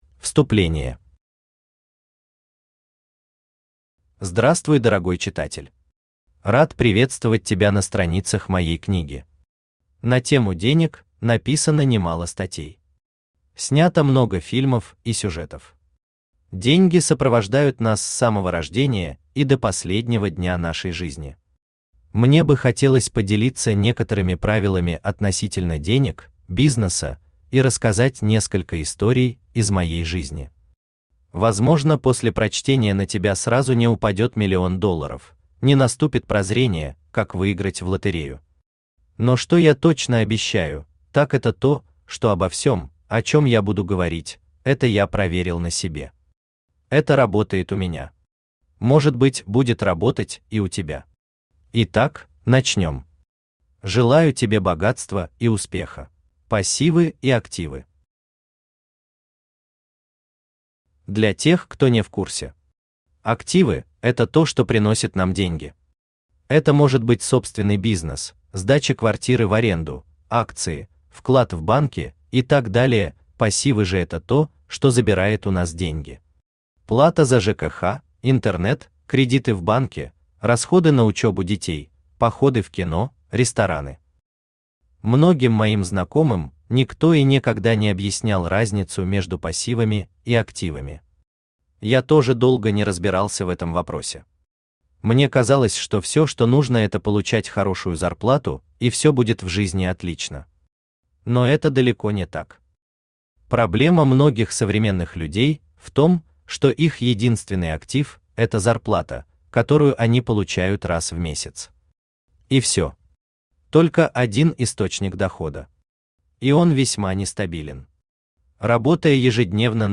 Аудиокнига Мой путь к деньгам | Библиотека аудиокниг
Aудиокнига Мой путь к деньгам Автор Николай Николаевич Неделько Читает аудиокнигу Авточтец ЛитРес.